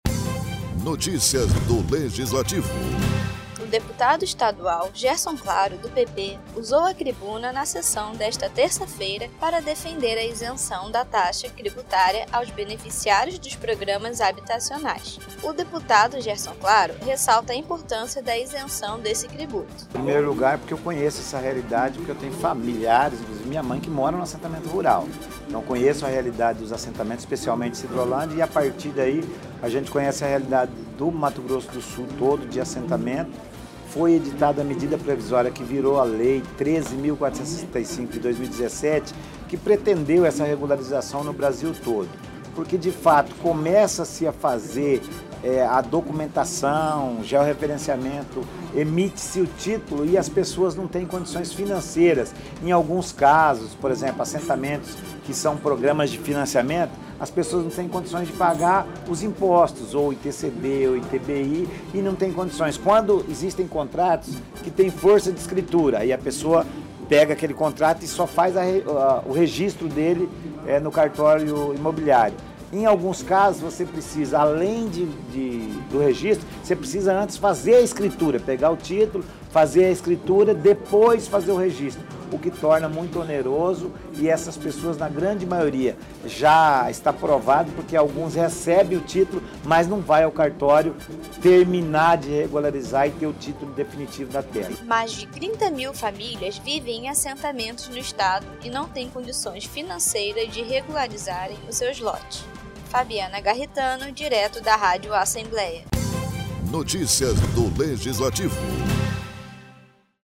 Na tribuna desta terça-feira, o deputado Gerson Claro, do PP defendeu a gratuidade de taxas cartoriais e tributárias a famílias beneficiarias do programas habitacionais.